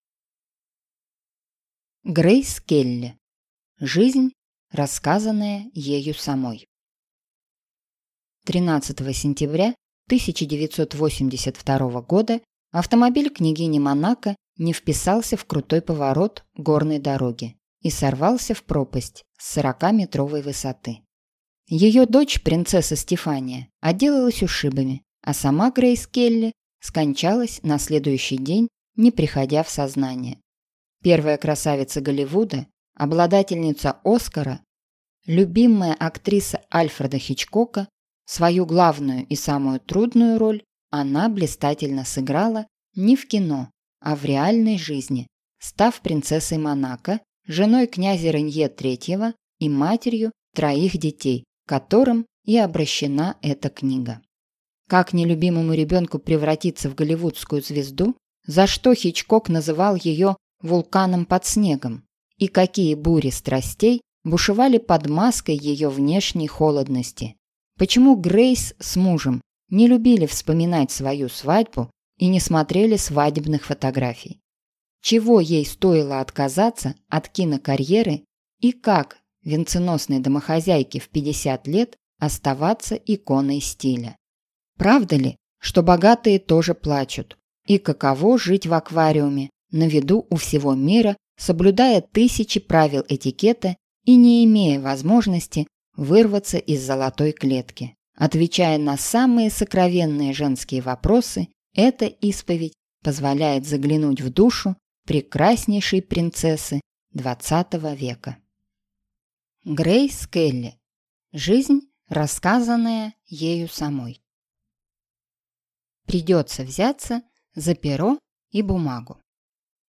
Аудиокнига Грейс Келли. Жизнь, рассказанная ею самой | Библиотека аудиокниг